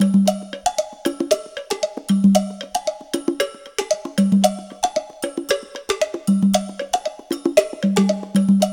CONGA BEAT25.wav